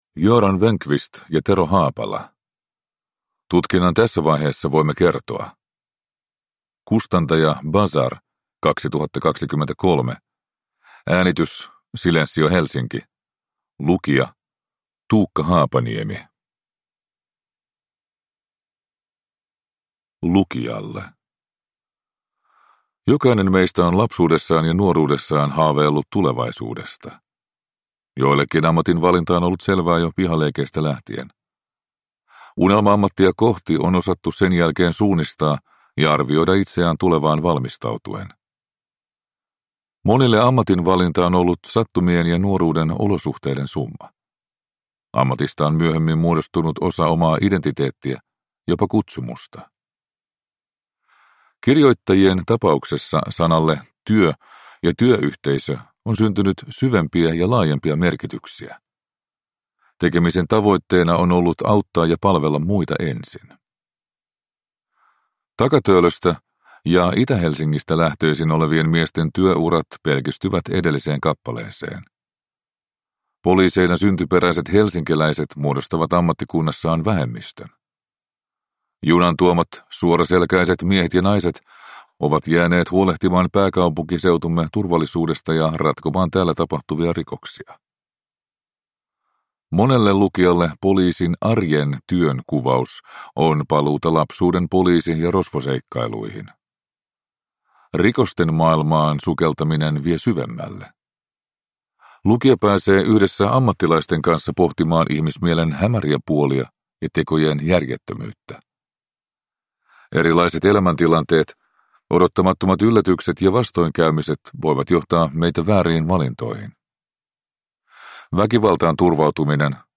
Tutkinnan tässä vaiheessa voimme kertoa – Ljudbok – Laddas ner